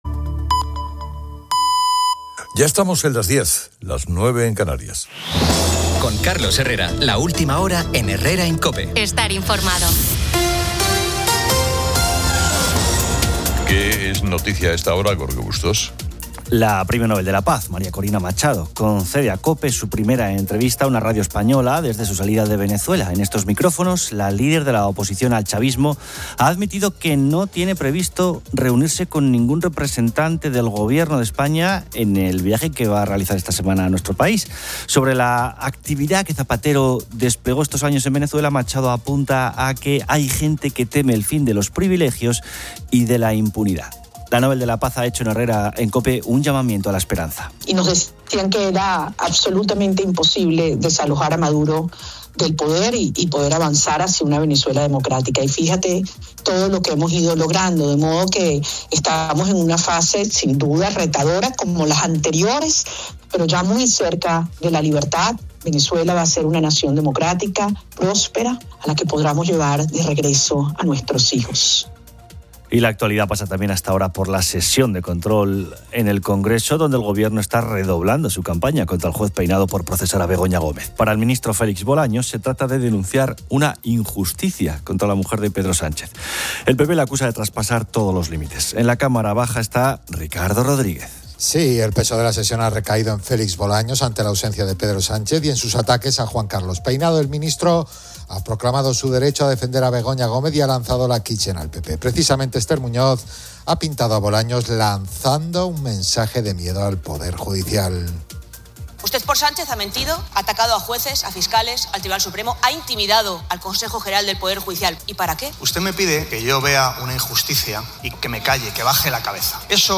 El programa inicia con la exclusiva entrevista a María Corina Machado, líder de la oposición venezolana y Premio Nobel de la Paz, quien desde España...
Además, el espacio invita a los oyentes a compartir anécdotas sobre objetos que se han llevado "sin querer", generando momentos de humor y cotidianidad.